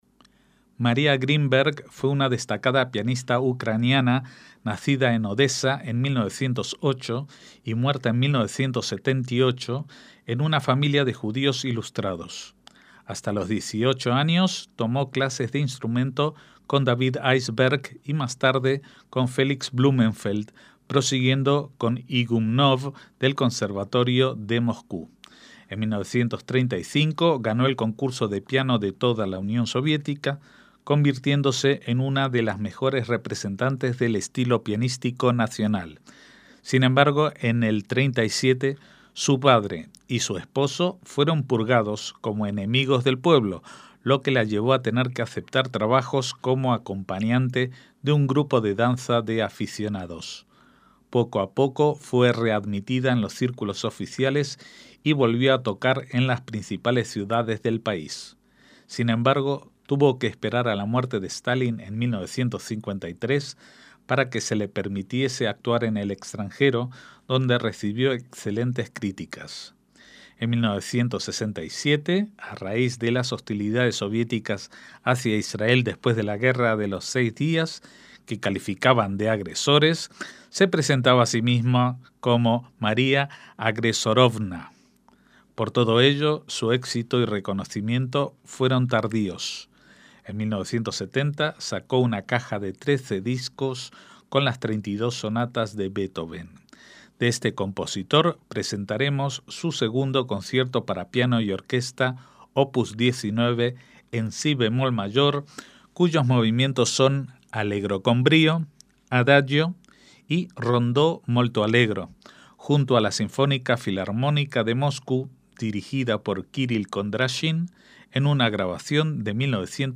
MÚSICA CLÁSICA - María Grínberg (1908 – 1978) fue una pianista nacida en Odesa (actual Ucrania), siendo su padre un erudito judío profesor de hebreo y su madre, profesora de piano.